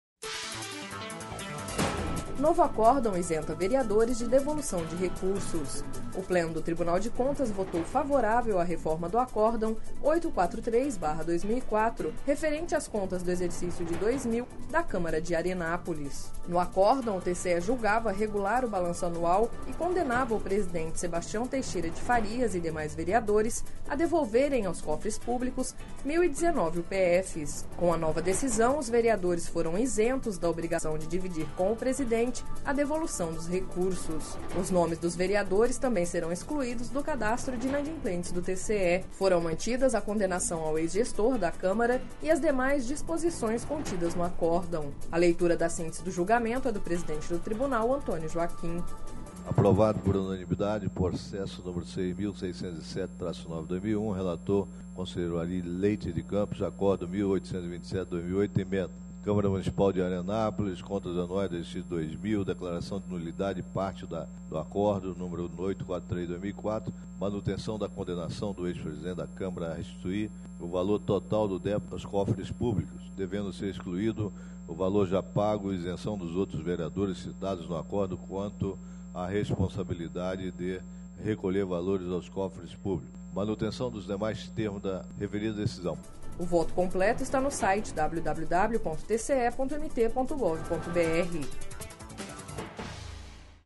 A leitura da síntese do julgamento é do presidente do Tribunal, Antonio Joaquim.